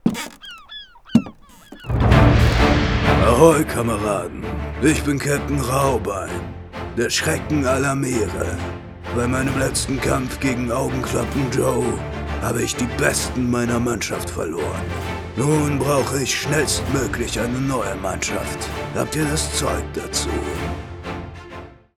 Hörprobe für Schatzsuche Kindergeburtstag:
Der gefürchtete und verwegene Captain Raubein wendet sich persönlich an die angetretenen jungen Piraten und bittet um ihre Hilfe.